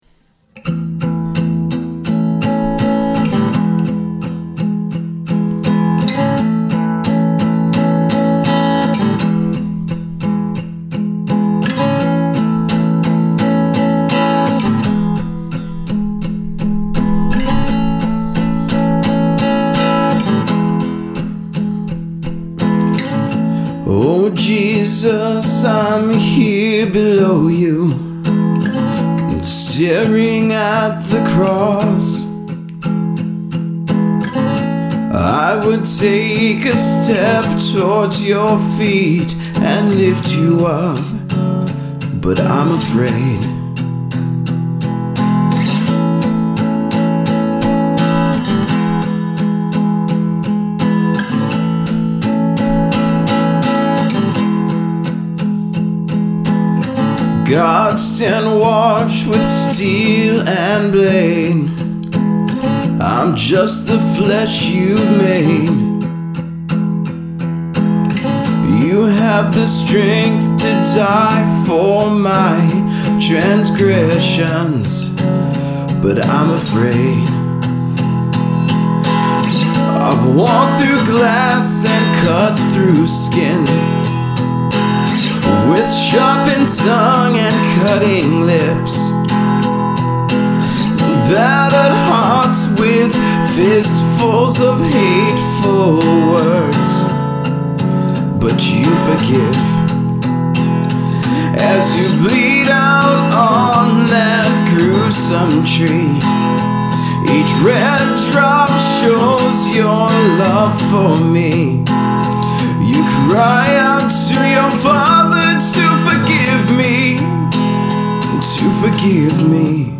Acoustic Mix
This mix is three tracks. Vocal Condenser mic at 12th fret of Acoustic Line in from jack on Acoustic For the mix I am going for an old "dated" sound hence what I'm trying to do with the Reverb / EQ I have a limiter set to "just" touch 0 at the mixes highest point.